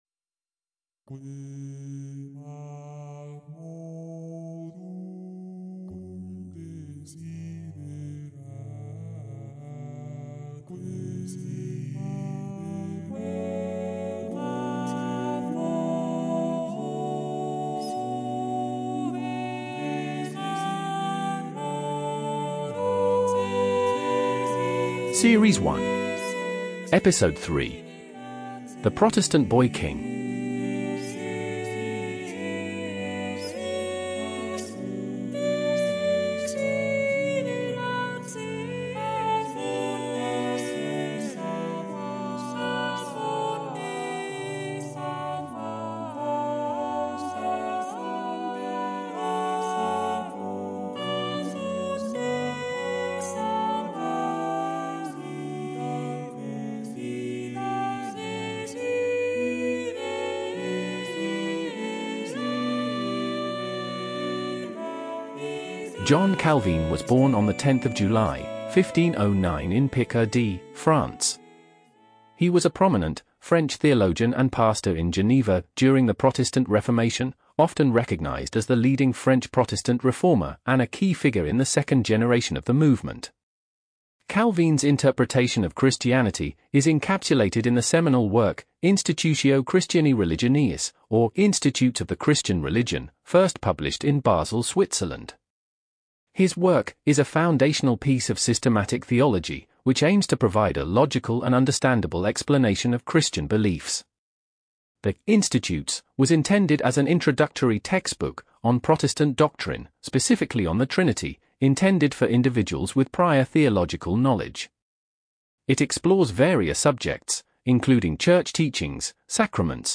Music • John Taverner: Quemadmodum , Psalm 42, c.1540.